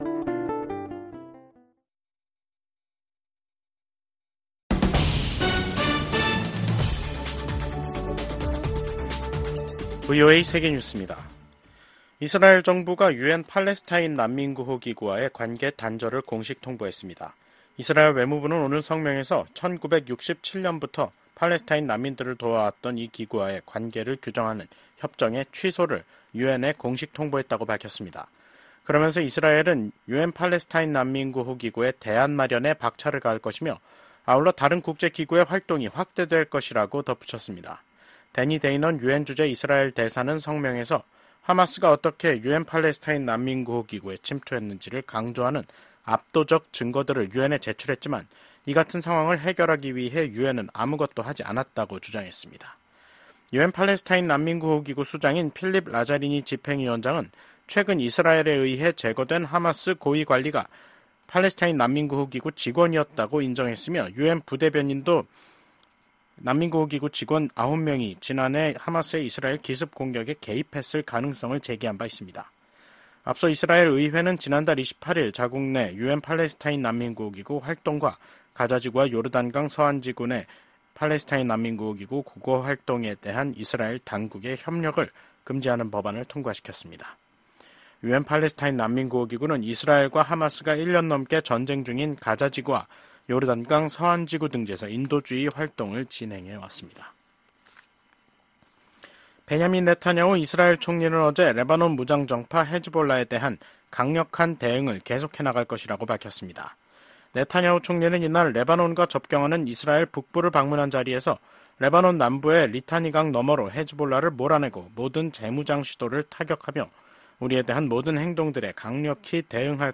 VOA 한국어 간판 뉴스 프로그램 '뉴스 투데이', 2024년 11월 4일 2부 방송입니다. 북한의 러시아 파병 문제가 국제적인 중대 현안으로 떠오른 가운데 북러 외교수장들은 러시아의 우크라이나 전쟁을 고리로 한 결속을 강조했습니다. 미국과 한국의 외교, 국방 수장들이 북러 군사협력 심화와 북한의 대륙간탄도미사일 발사를 강력히 규탄했습니다. 10개월 만에 재개된 북한의 대륙간탄도미사일 발사를 규탄하는 국제사회의 목소리가 이어지고 있습니다.